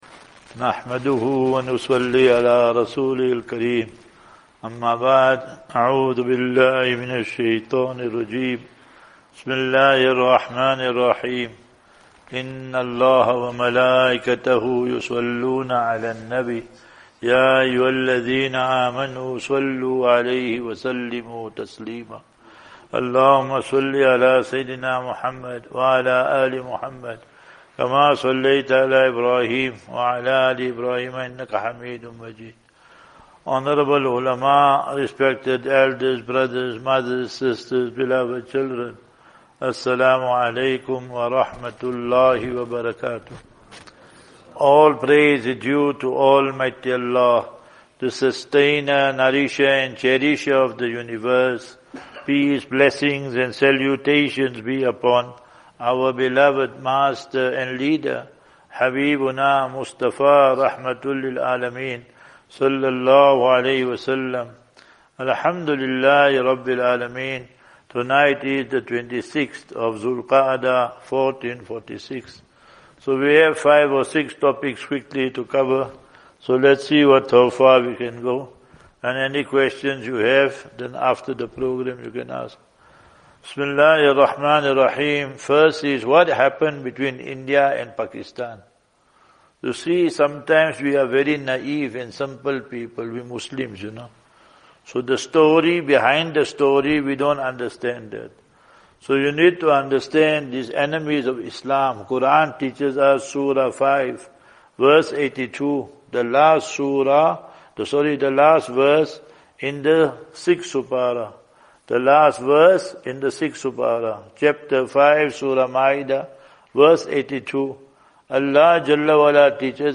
24 May 24 May 2025 - Trichardt Jami Masjid
Lectures